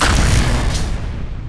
SUTeslaStorm_expa.wav